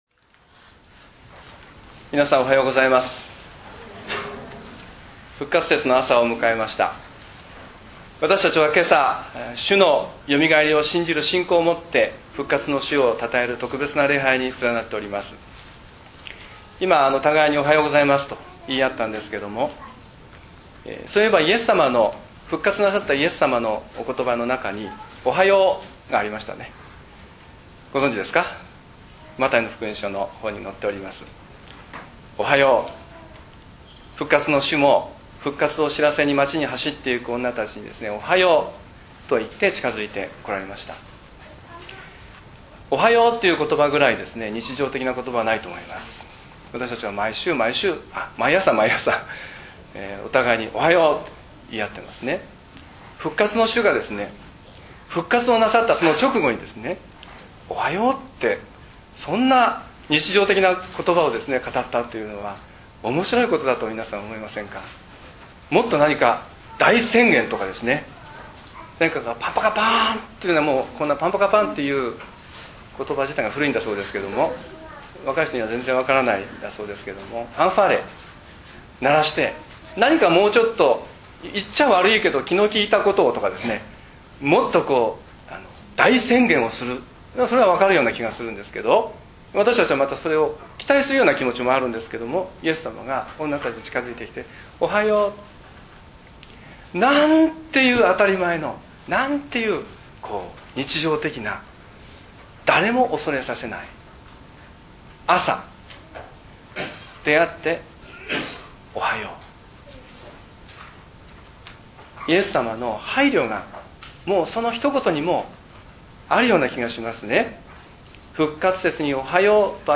礼拝宣教録音－復活の主のことば